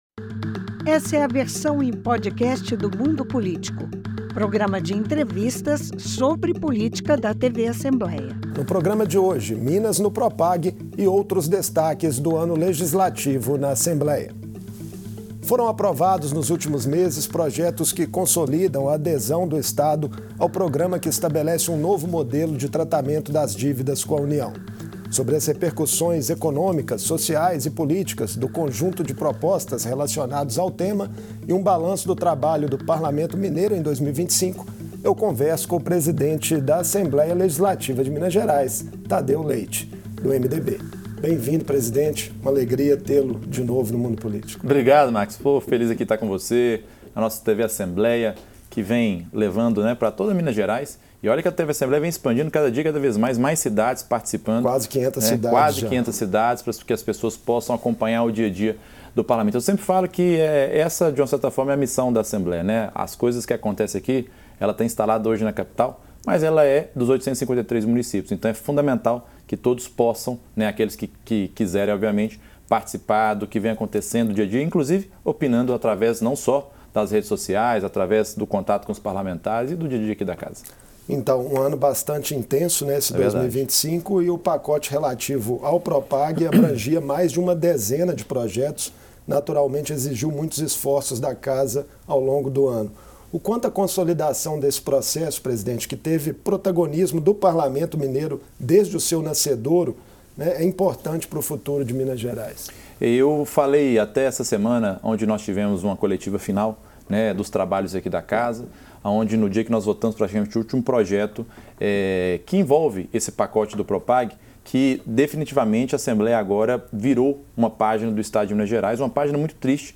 O presidente da Assembleia Legislativa, deputado Tadeu Leite, conversou com o jornalista